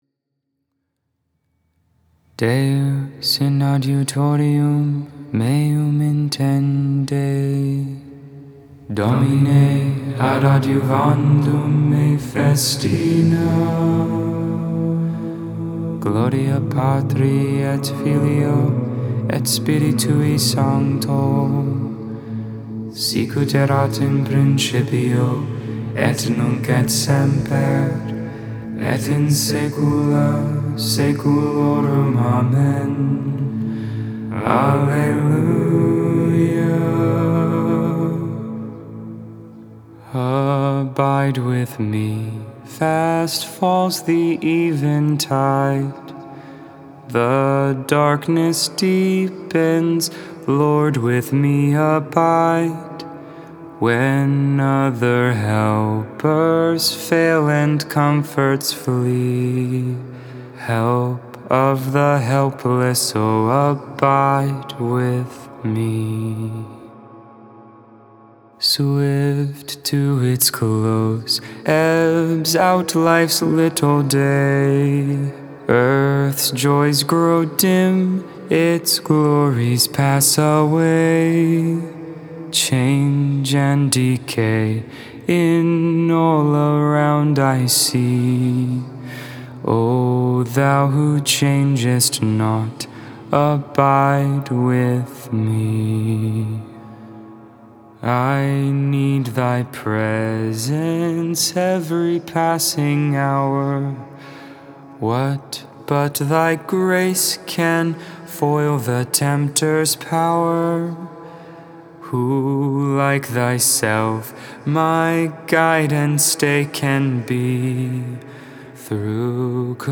Vespers, Evening Prayer for the 3rd Friday of Eastertide.